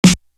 Premium Snare.wav